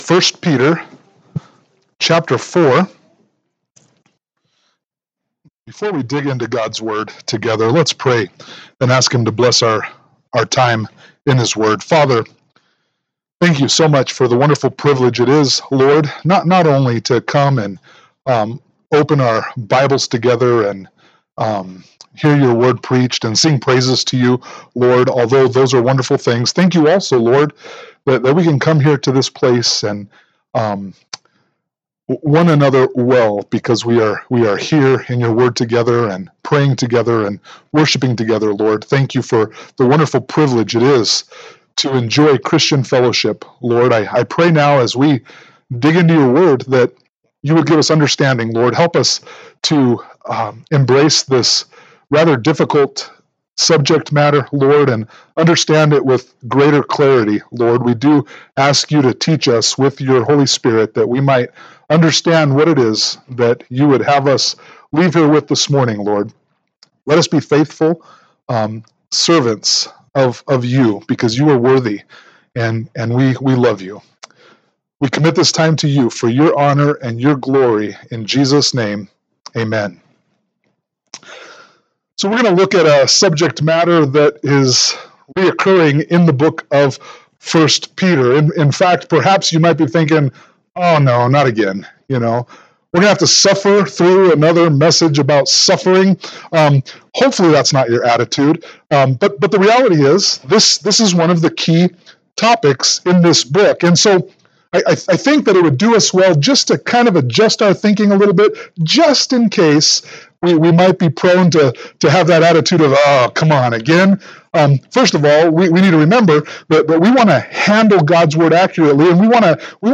1 Peter 4:12-19 Service Type: Sunday Morning Worship « 1 Peter 4:7-11 1 Peter 5:1-5